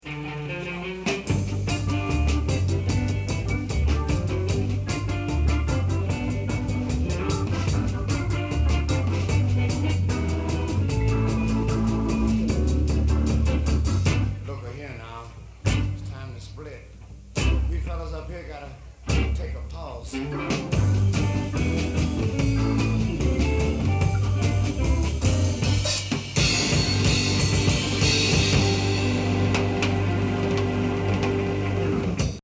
Live*
* Recorded at the Terrace Supper Club